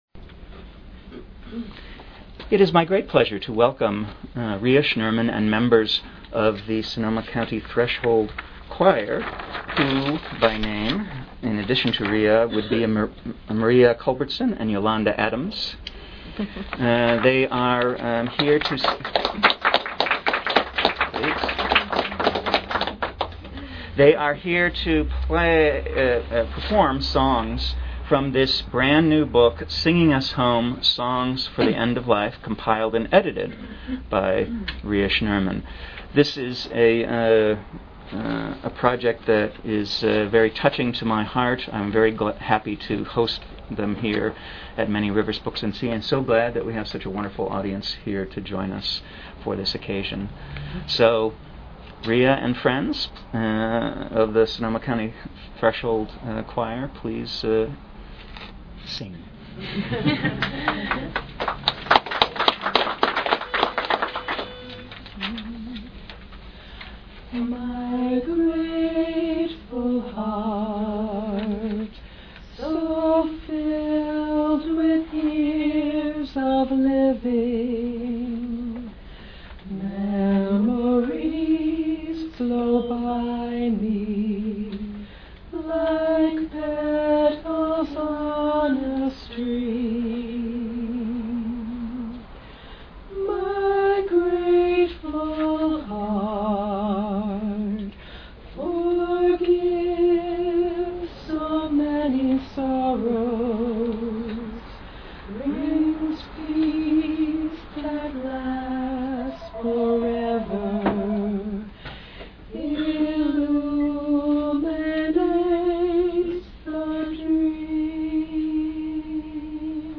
Archive of an event at Sonoma County's largest spiritual bookstore and premium loose leaf tea shop.
They will be discussing the book, as well as singing and teaching some of the songs.